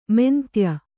♪ mentya